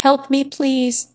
Linear-Spectrogram은 Griffin-Lim Algorithm이라는 음성 재구성 알고리즘을 통해서 음성으로 변환
1. 느낌표(!) 사용시 변화 테스트